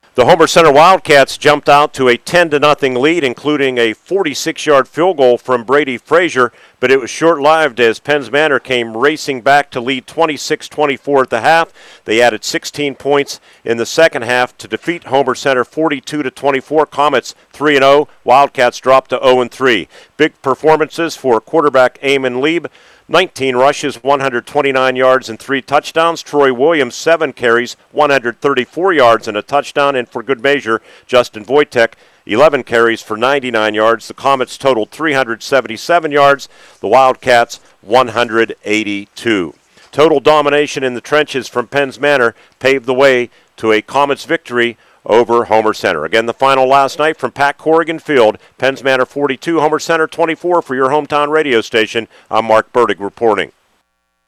recap